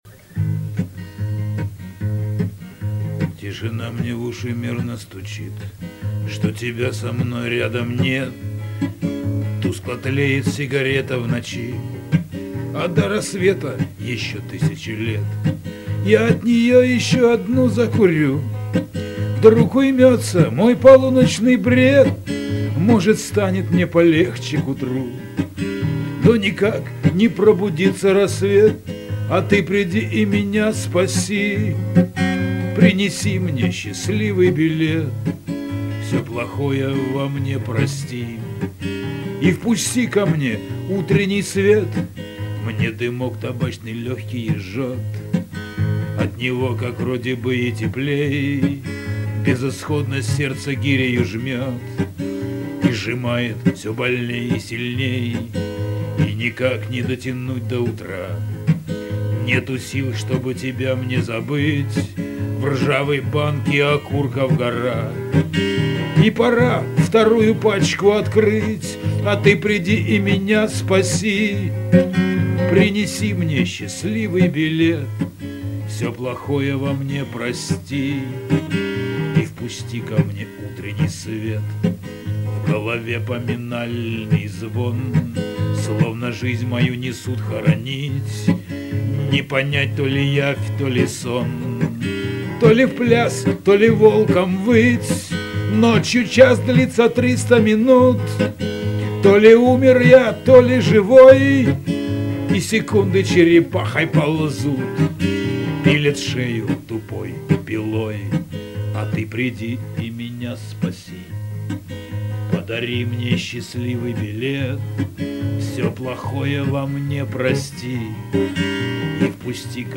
Шансон
Исполнение под гитару